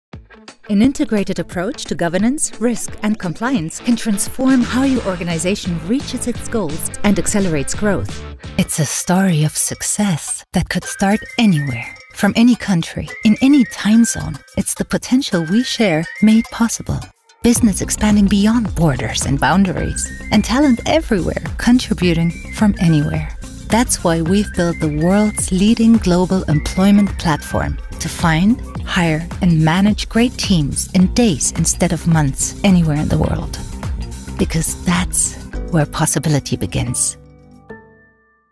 Female
Approachable, Bright, Character, Confident, Conversational, Corporate, Engaging, Friendly, Natural, Reassuring, Smooth, Versatile, Warm
Microphone: Rode NT1
Audio equipment: Focusrite Scarlett audio interface, pop filter, soundproof cabin